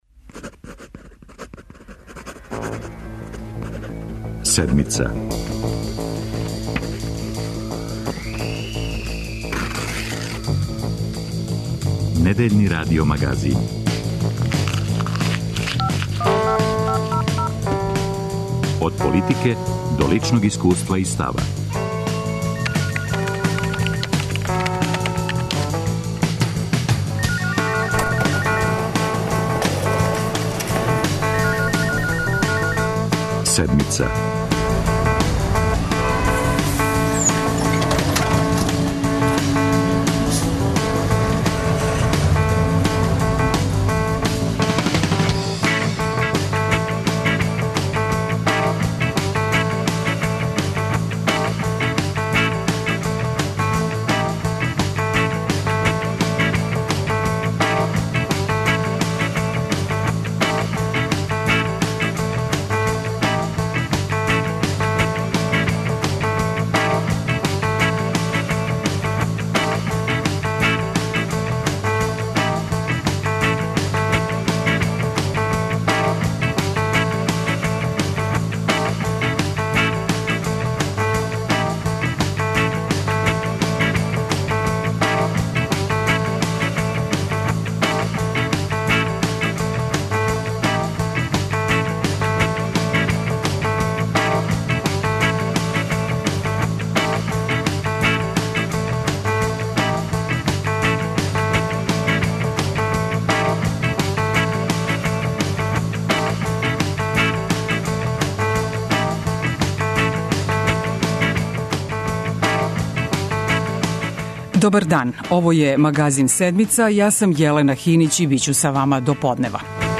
У емисији тражимо одговор на питање: Ко профитира од српске пољопривреде? У разговору са гостима покушаћемо да одговоримо да ли Владина аграрна политика треба да решава социјалне проблеме или да ствара амбијент за исплатив бизнис.